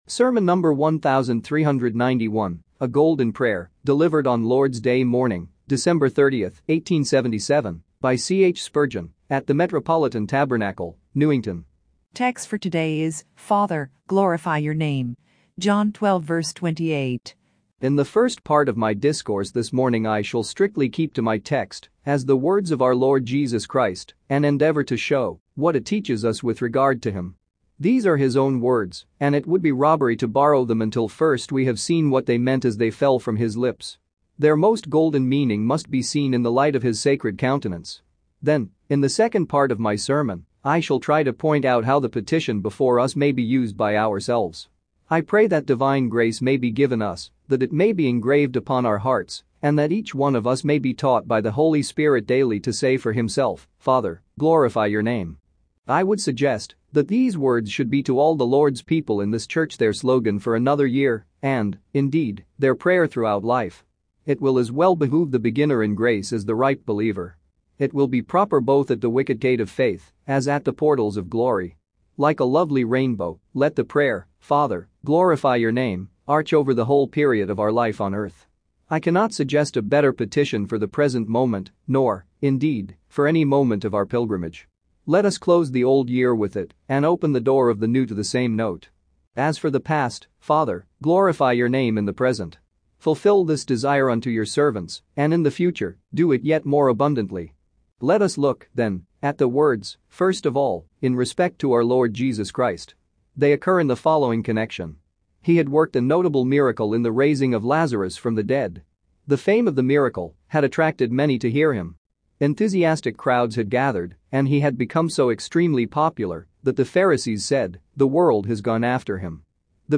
Sermon number 1,391, A GOLDEN PRAYER